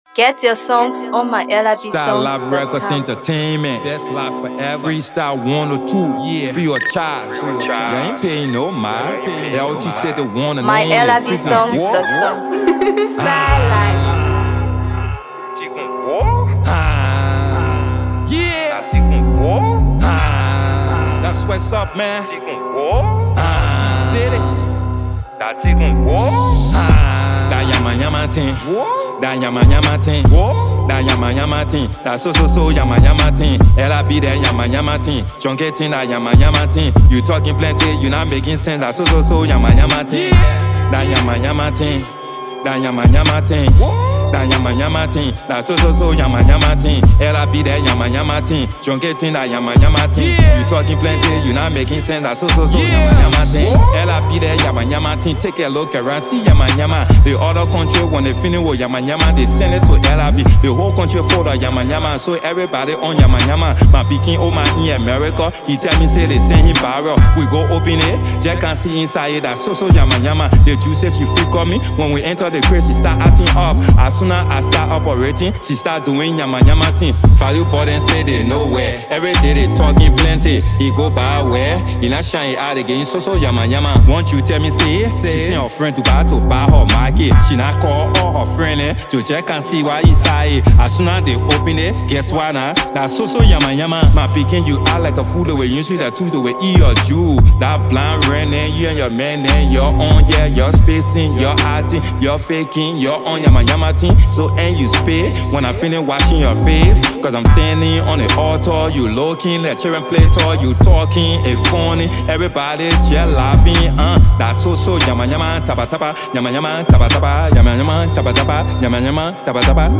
HipcoMusic
a high-energy track